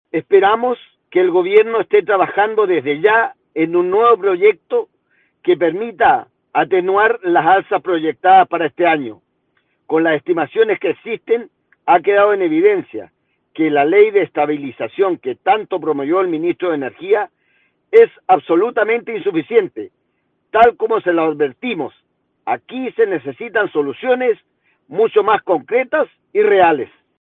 Al respeto el diputado Bórquez, indicó: